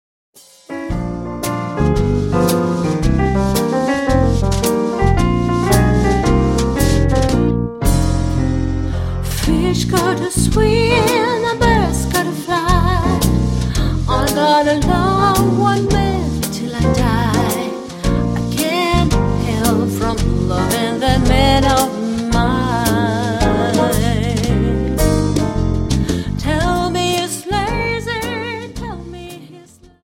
Dance: Slowfox